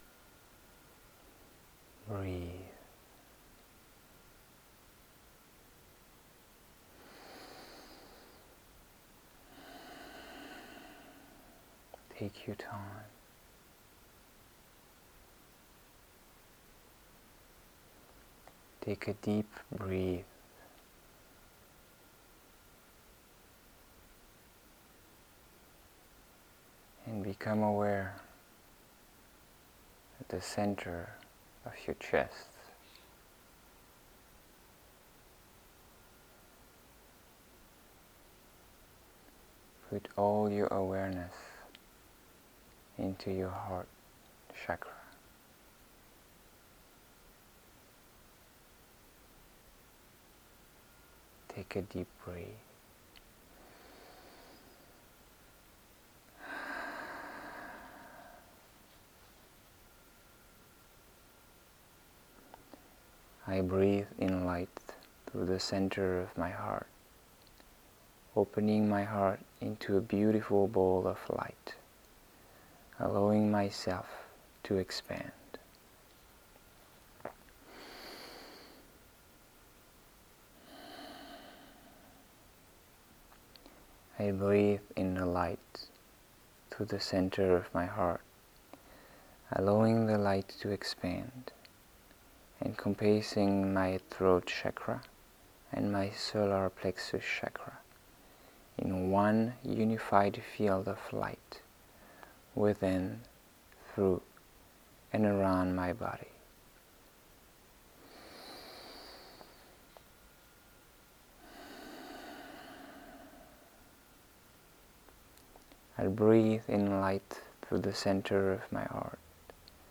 Guided Activation/Meditations